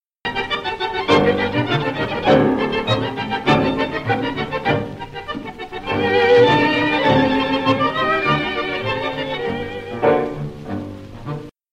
Milonga